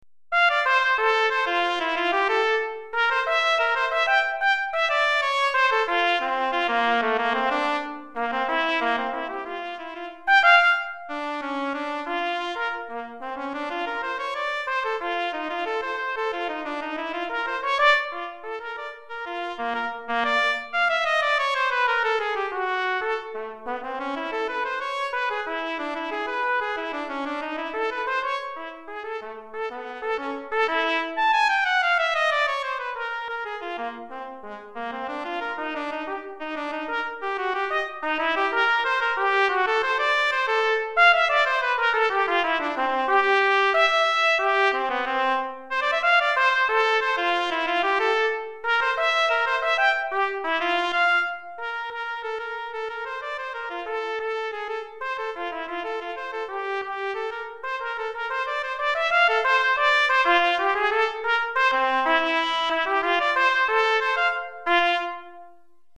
Trompette Solo